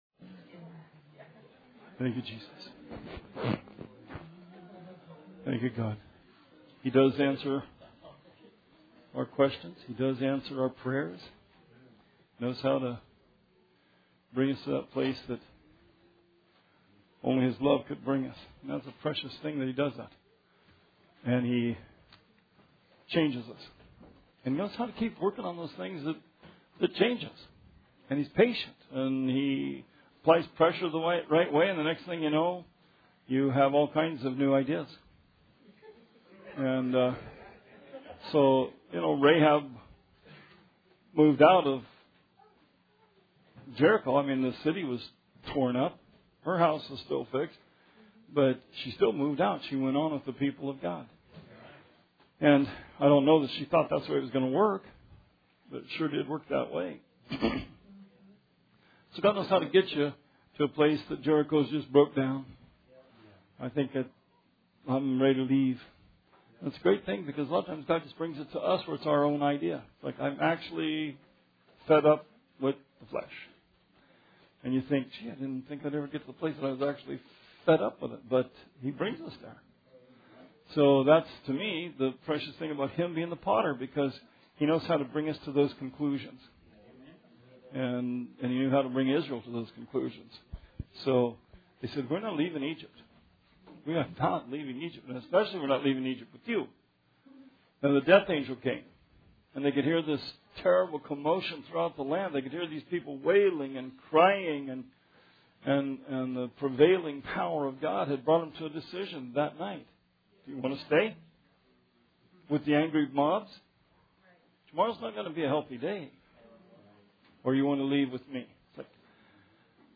Bible Study 4/12/17